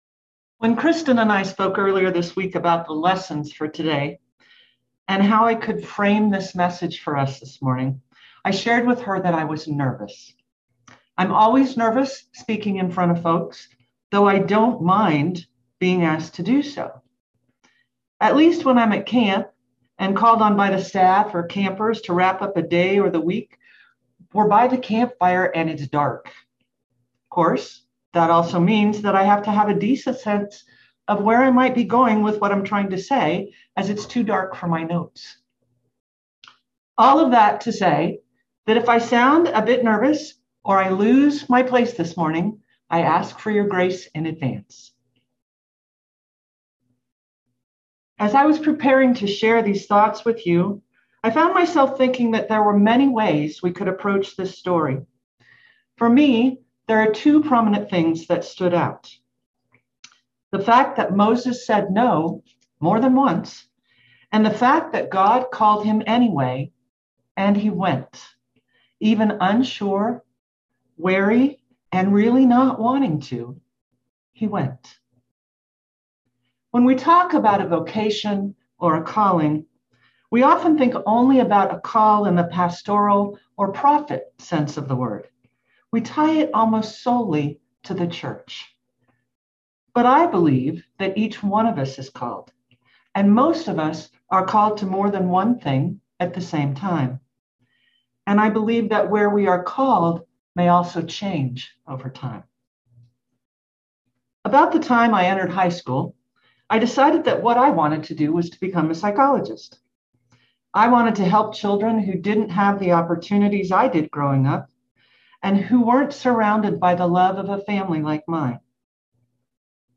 Passage: Exodus 3:9-12, Exodus 4:1-17 Service Type: Sunday Morning Download Files Bulletin « Holy Communion